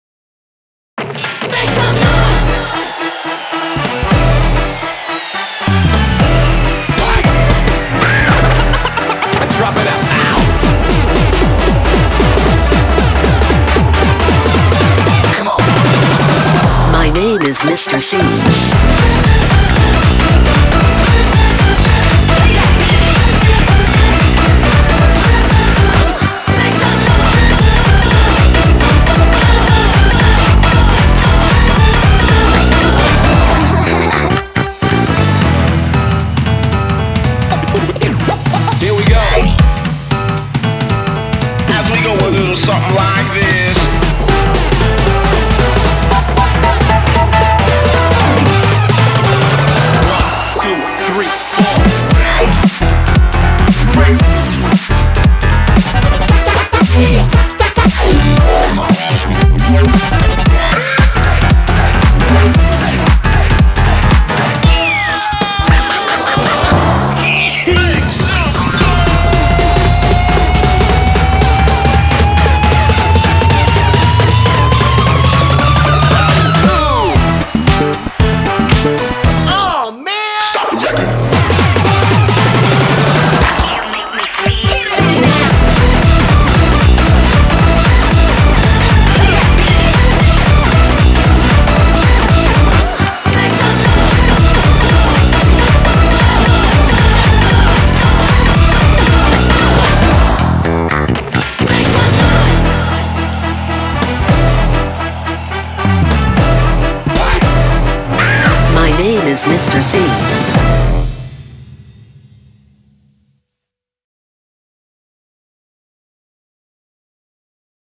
BPM115-230
Audio QualityPerfect (High Quality)